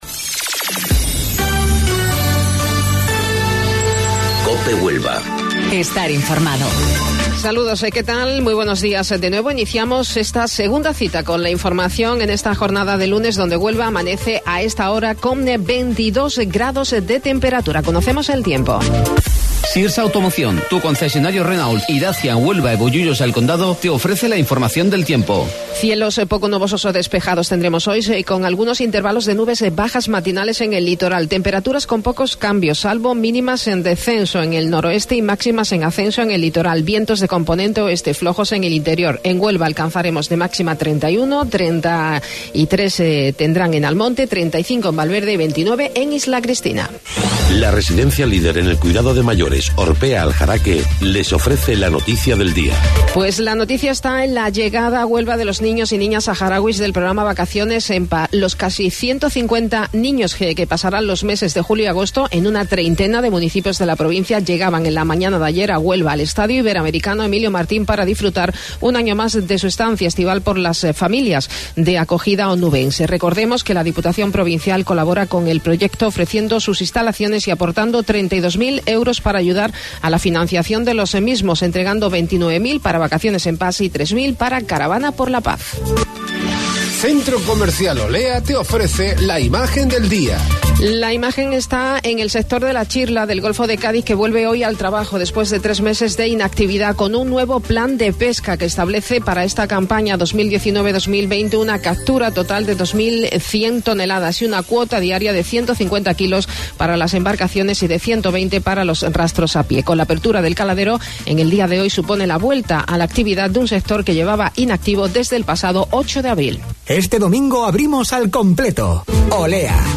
AUDIO: Informativo Local 08:25 del 1 de Julio